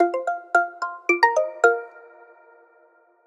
call_incoming.mp3